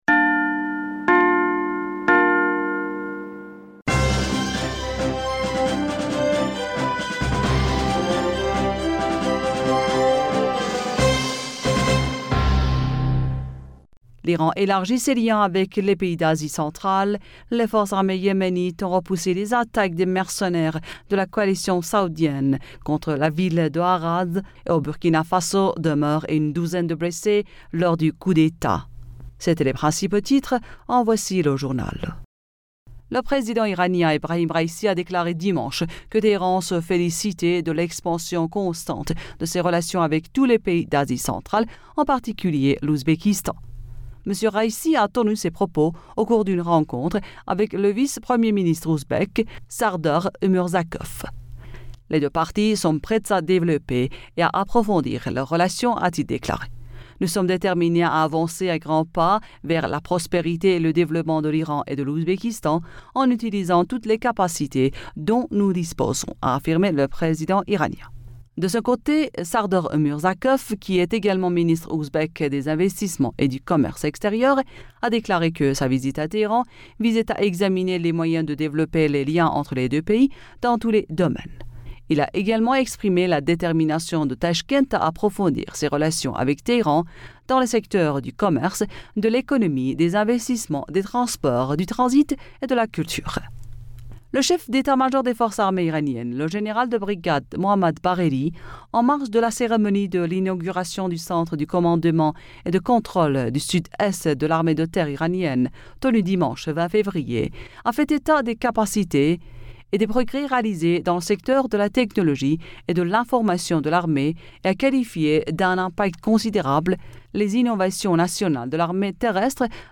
Bulletin d'information Du 21 Fevrier 2022